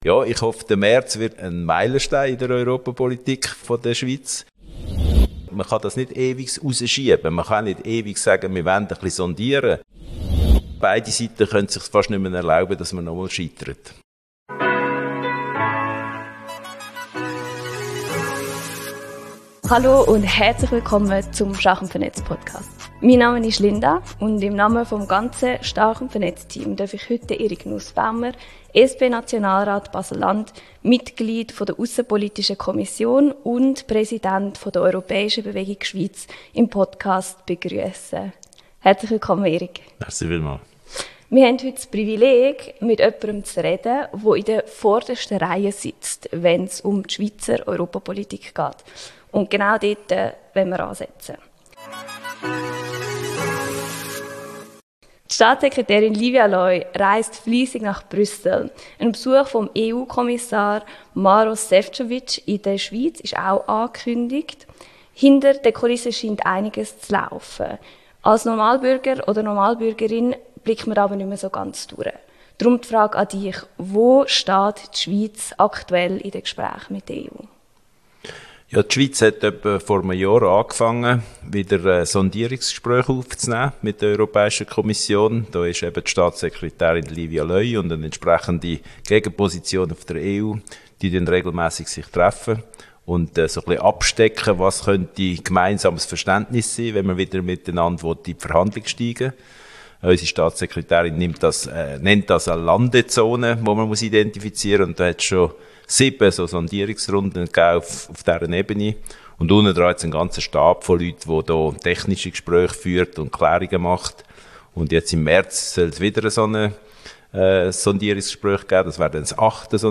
Doch wo stehen wir wirklich in den Gesprächen mit der EU? stark+vernetzt hat ein Mitglied der Aussenpolitischen Kommission des Nationalrats dazu befragt.